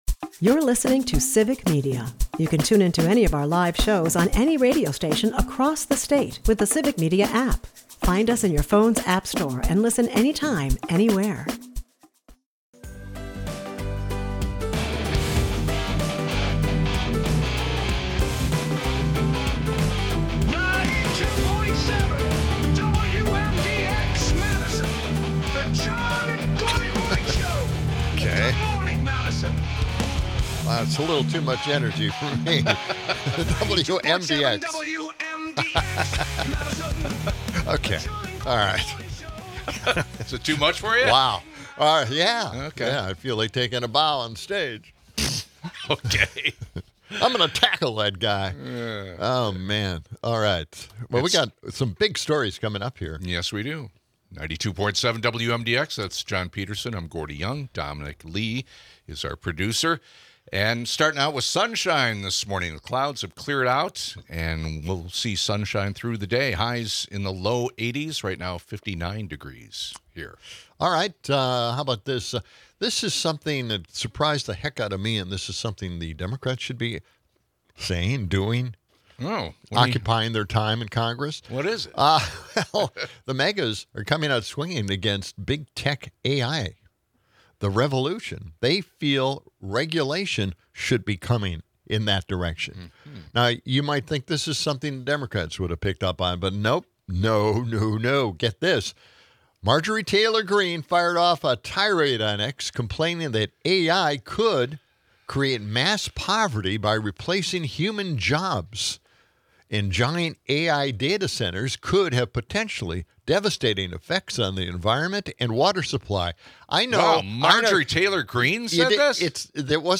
A lively discussion ensues, with callers sharing personal stories to counter Kirk's stance. The episode rounds off with quirky news, including a nurse saving a drunk raccoon and a poignant Bob Uecker tribute.